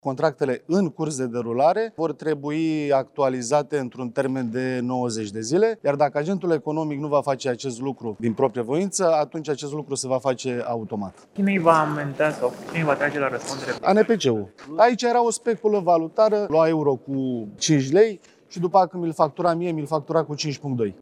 Dacă vor fi aprobate de Parlament, noile prevederi se vor aplica și contractelor aflate în derulare, a precizat deputatul USR